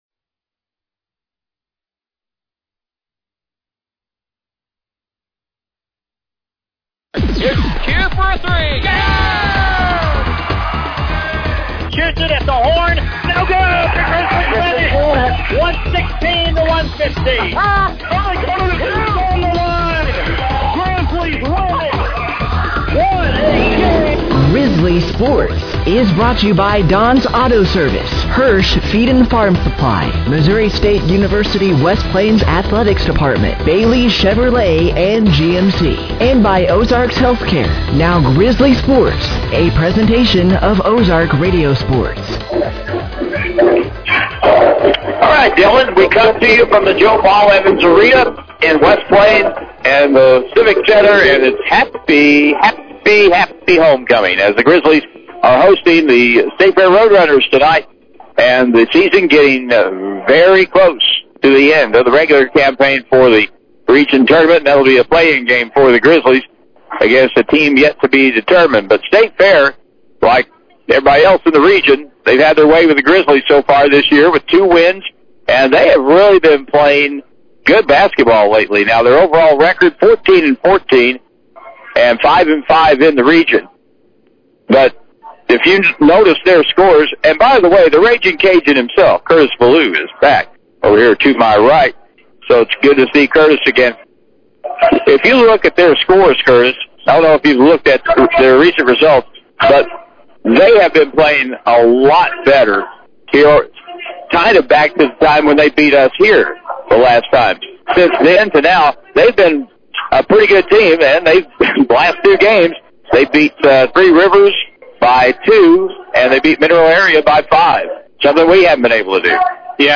The Missouri State West Plains Grizzlies hosted the State Fair Roadrunners for Homecoming at The West Plains Civic Center this past Saturday night, February 21st, 2026